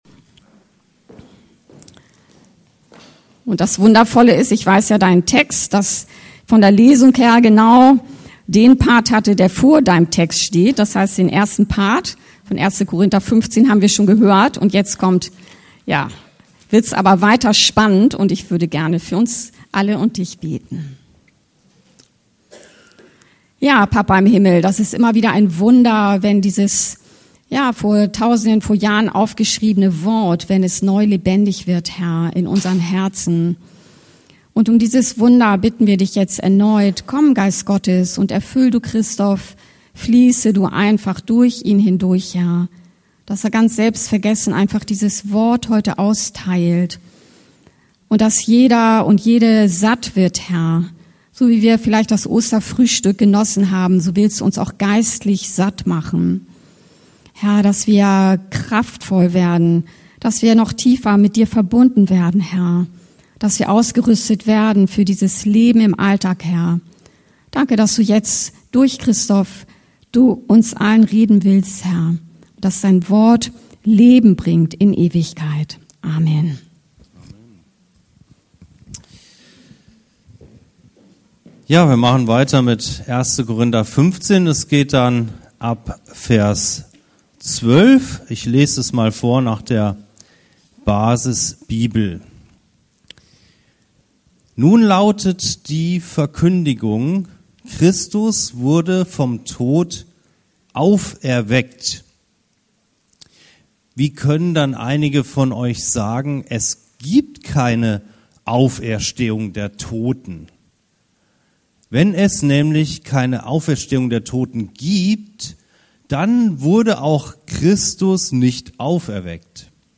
Predigt zu Ostersonntag - Die Auferstehung, 1.Kor 15,12-19 ~ Anskar-Kirche Hamburg- Predigten Podcast